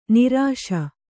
♪ nirāśa